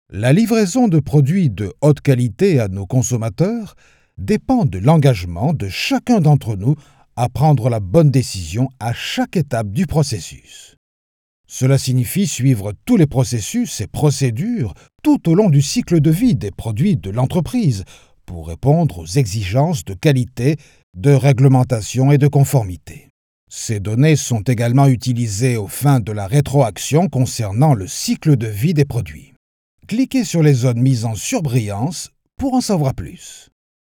Une voix mature, posée, élégante
Sprechprobe: eLearning (Muttersprache):
A mature and elegant voice